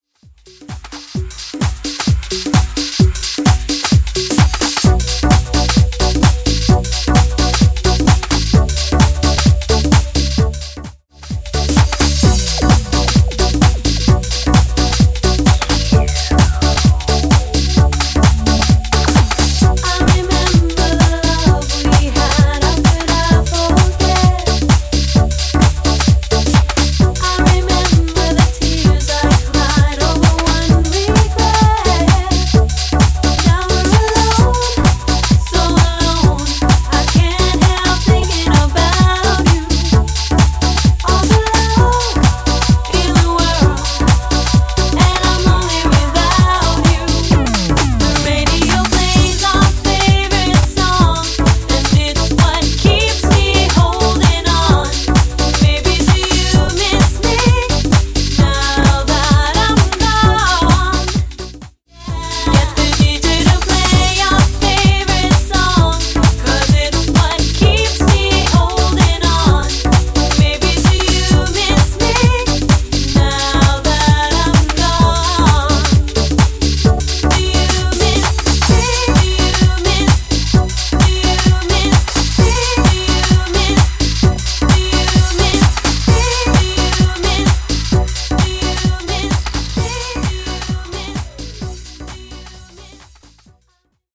BPM: 130 Time